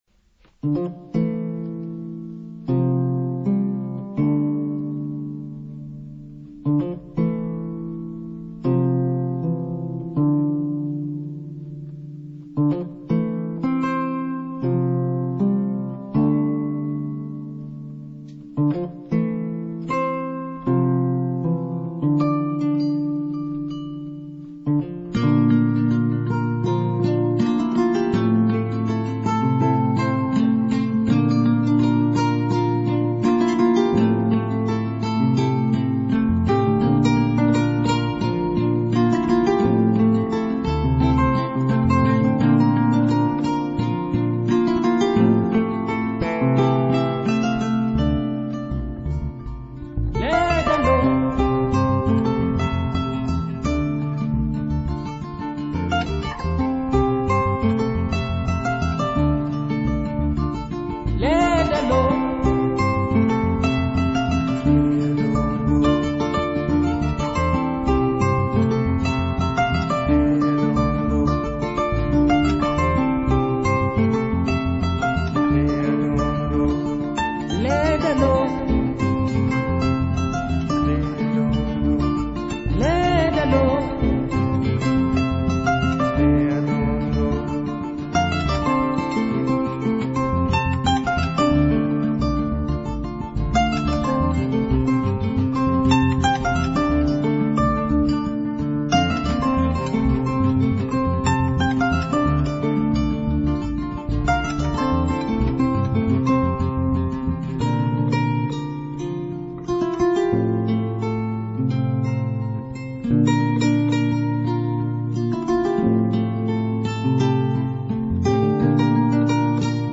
jeder Vorstellung live auf der Kora, einem afrikanischen